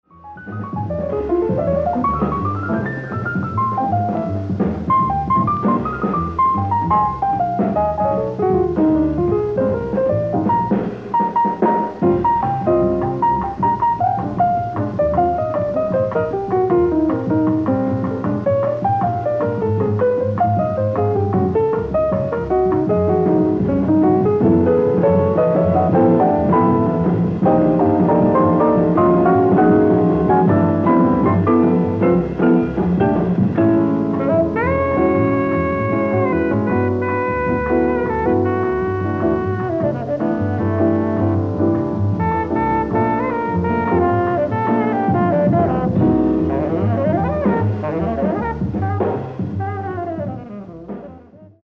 ライブ・アット・オスロ、ノルウェー
※試聴用に実際より音質を落としています。